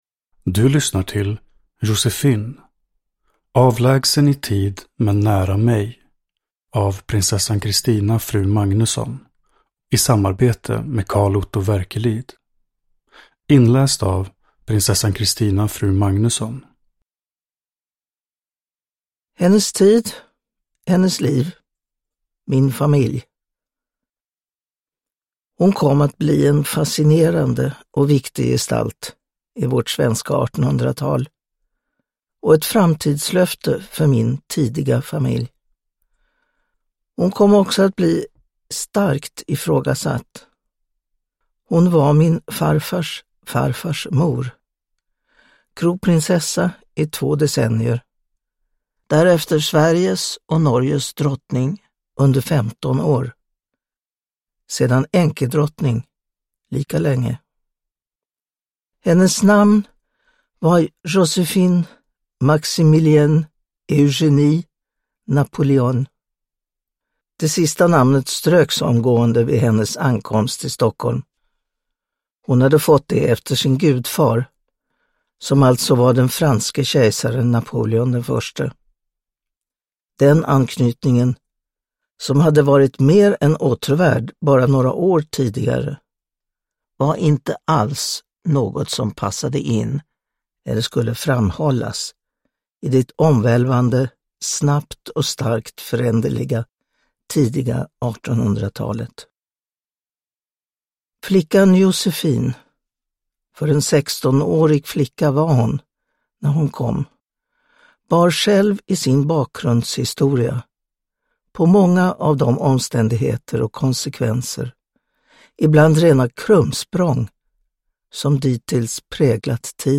Joséphine : avlägsen i tid - men nära mig – Ljudbok – Laddas ner
Uppläsare: Prinsessan Christina Magnuson